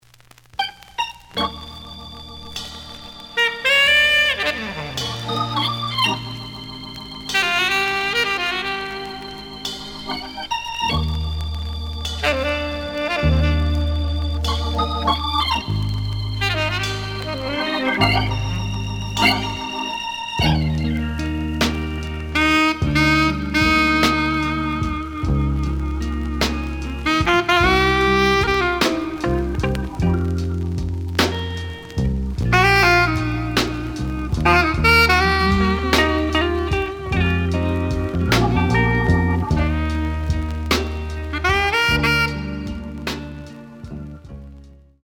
The audio sample is recorded from the actual item.
●Genre: Jazz Funk / Soul Jazz
Slight edge warp.